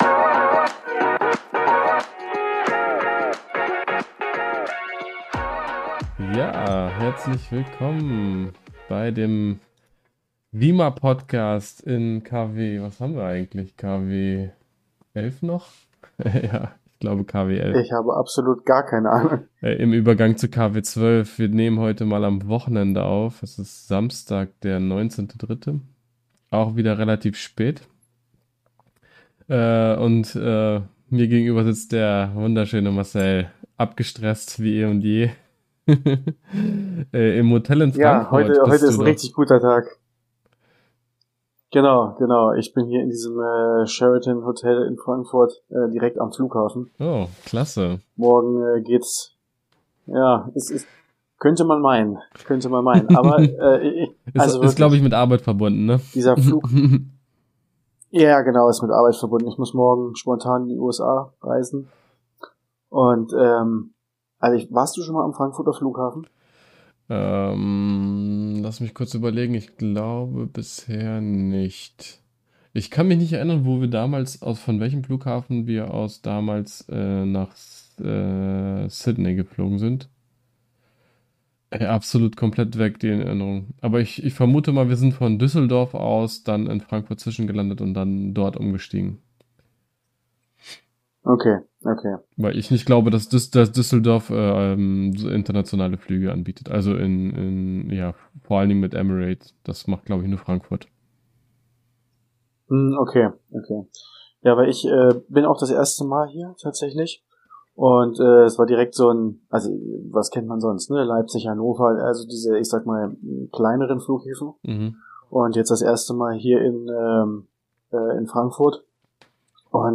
PPS: Da ich ein mobiles Setup zum aufnehmen benutze, ist die Tonqualität auf meiner Seite nicht perfekt.